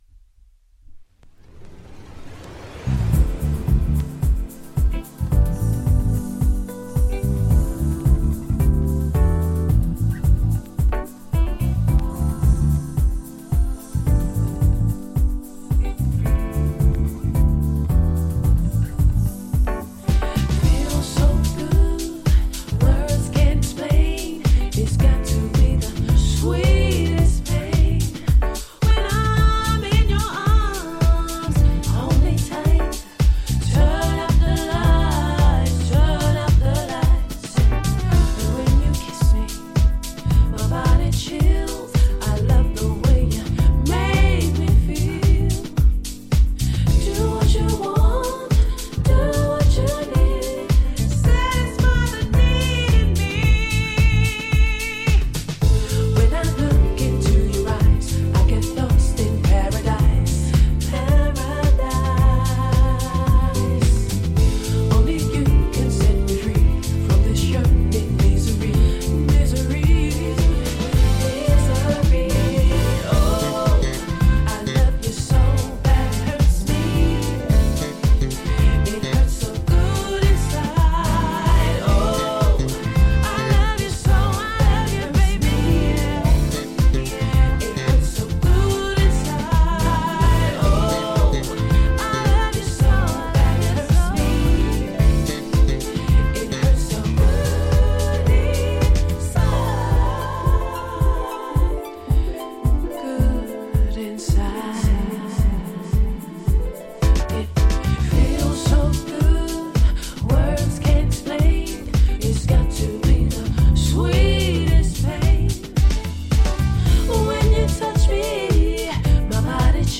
イギリスのフィメール・ソウル・シンガー
ジャンル(スタイル) SOULFUL HOUSE / UK SOUL / R&B